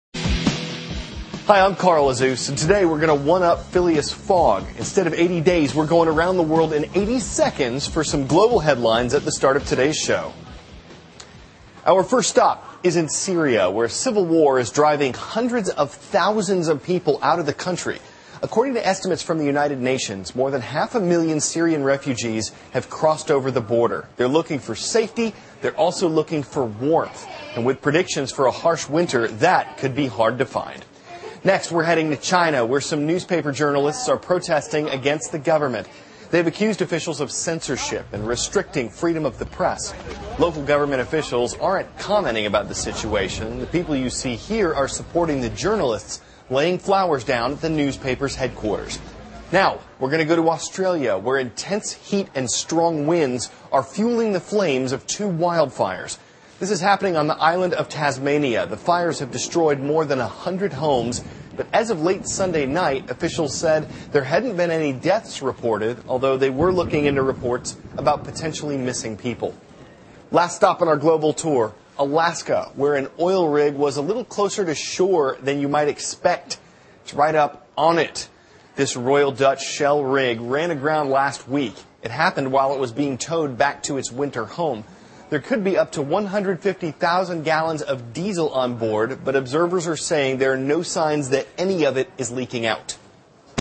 cnn student news,80秒看世界头条新闻